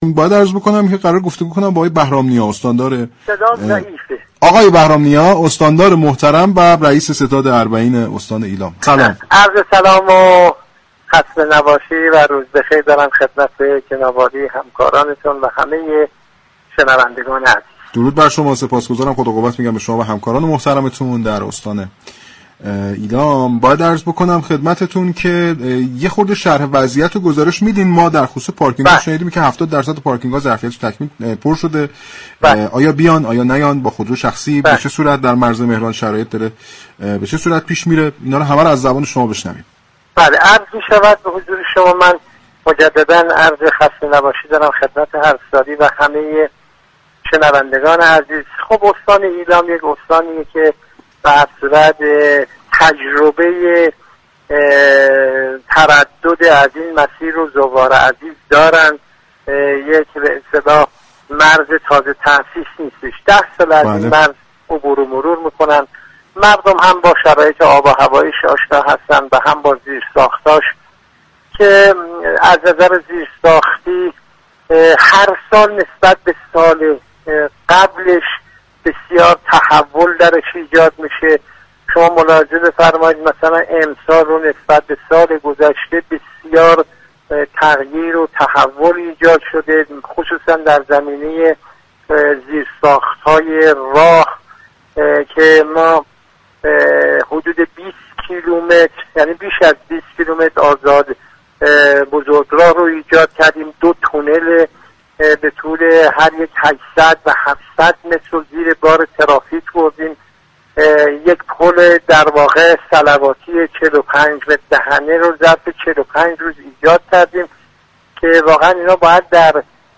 به گزارش پایگاه اطلاع رسانی رادیو تهران، حسن بهرام‌نیا استاندار و رئیس ستاد اربعین استان ایلام در گفت و گو با «تهران من» اظهار داشت: زیرساخت‌های تردد خودور در استان ایلام نسبت به سال گذشته گسترش پیدا كرده است.